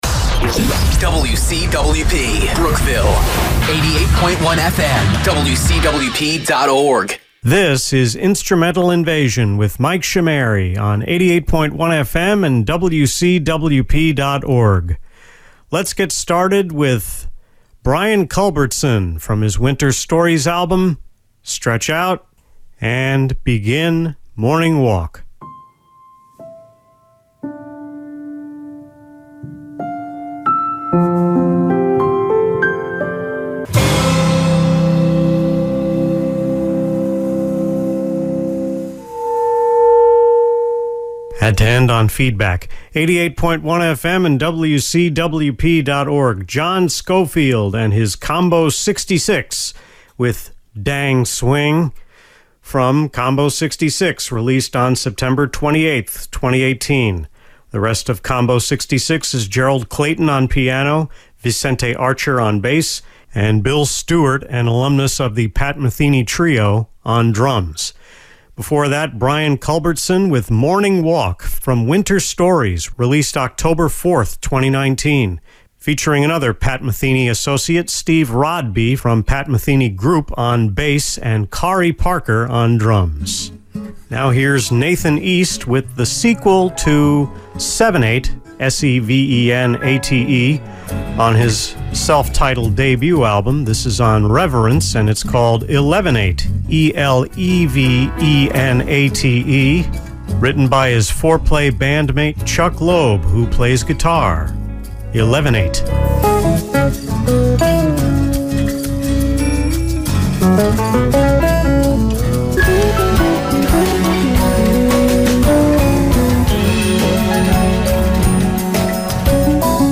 I had to redo talk breaks for the second hour’s first segment on the morning of April 30 due to a factual error in the original record.
The May 27, 2020, Instrumental Invasion on WCWP was #9 overall.